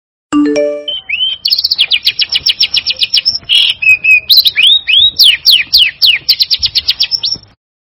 Nada dering Burung Kacer
Keterangan: Nada dering Burung Kacer, bisa diunduh dan digunakan sebagai nada dering, notifikasi WA, serta suara pemberitahuan untuk iPhone dan Android.
nada-dering-burung-kacer-id-www_tiengdong_com.mp3